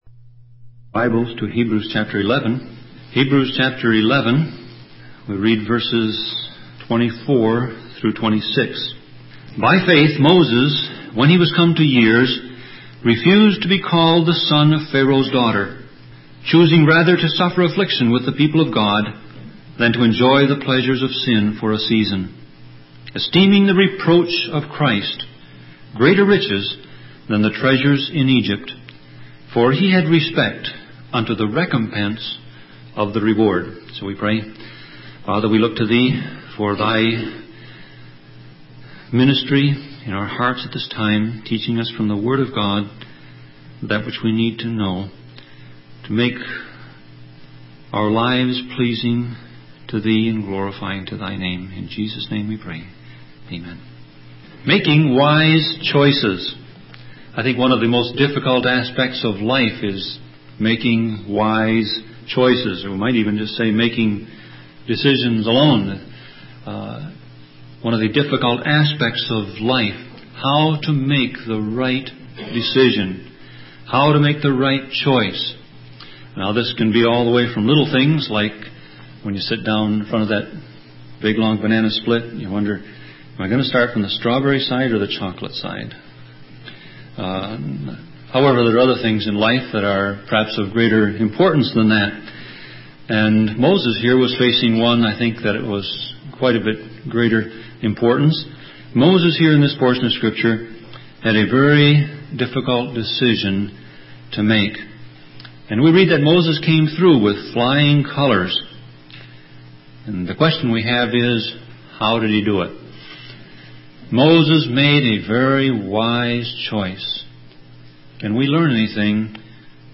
Sermon Audio Passage: Hebrews 11:24-26 Service Type